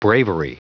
Prononciation du mot bravery en anglais (fichier audio)
Vous êtes ici : Cours d'anglais > Outils | Audio/Vidéo > Lire un mot à haute voix > Lire le mot bravery
Prononciation du mot : bravery